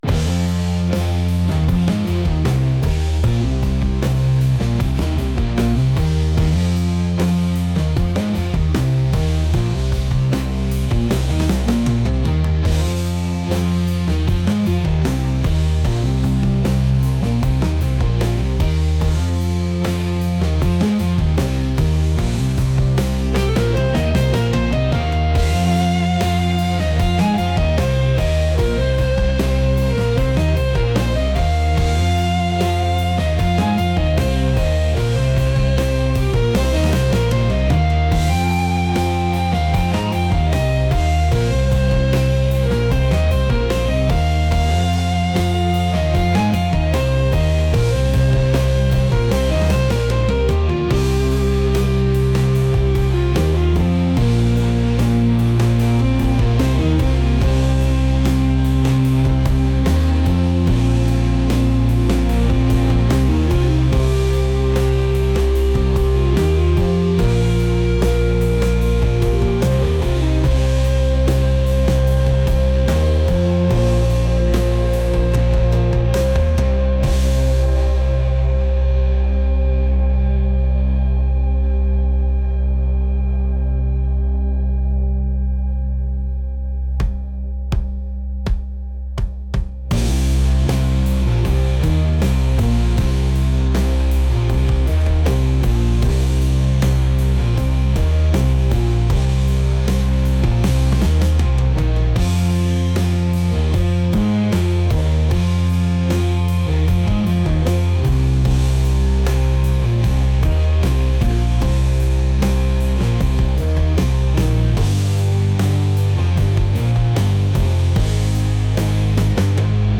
rock | heavy